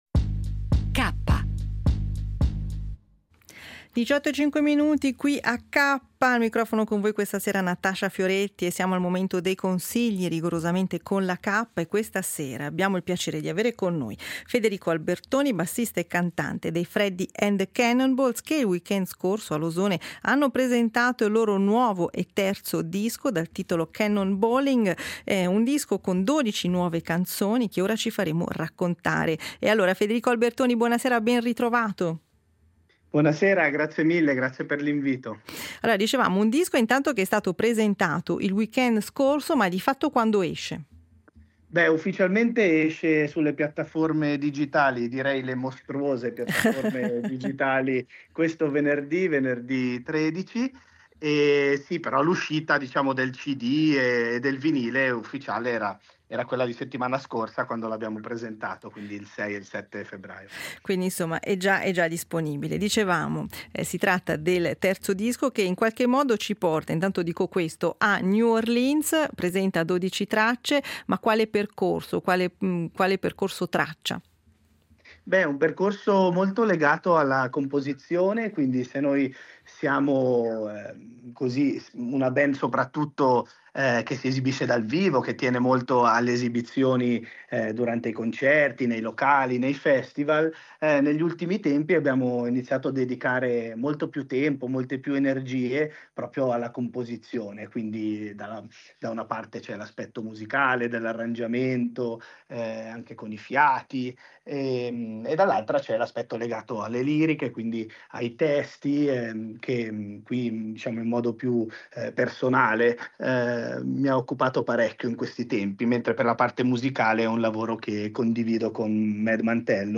Freddie & The Cannonballs ospiti negli studi di Rete Tre